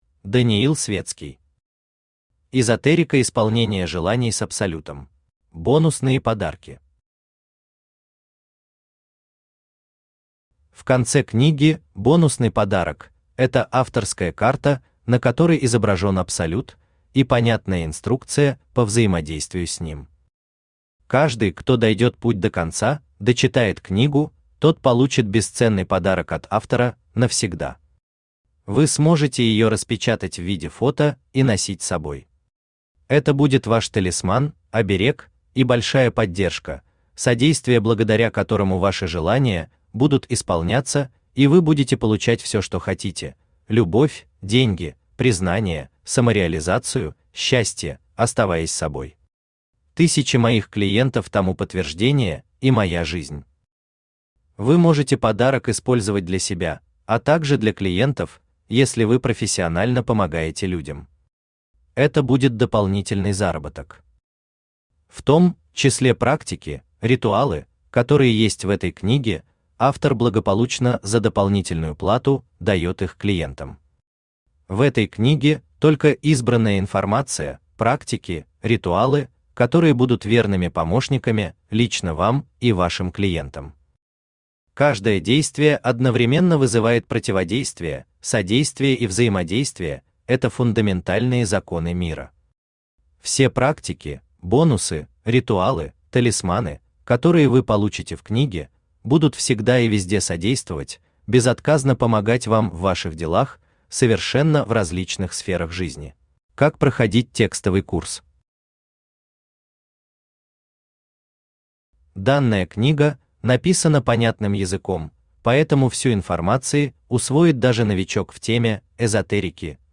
Аудиокнига Эзотерика исполнения желаний с Абсолютом | Библиотека аудиокниг
Aудиокнига Эзотерика исполнения желаний с Абсолютом Автор Даниил Светский Читает аудиокнигу Авточтец ЛитРес.